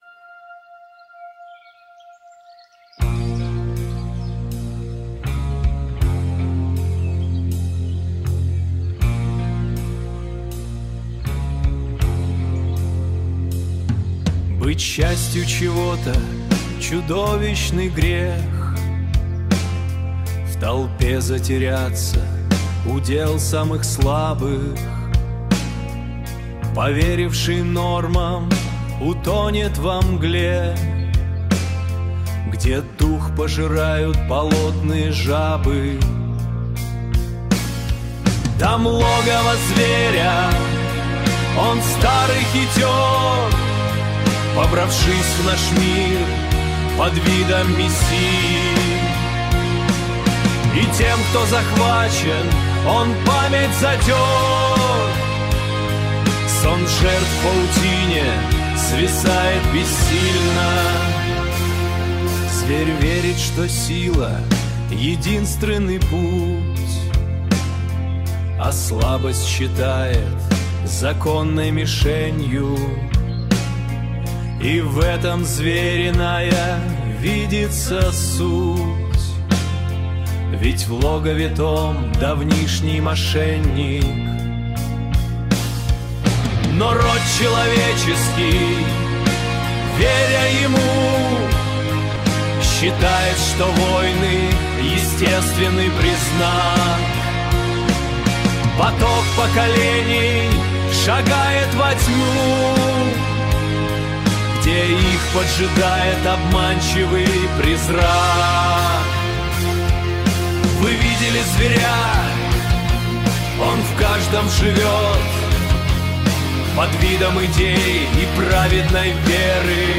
Logovo-Zverya-Heavy-Bass-Gothic.mp3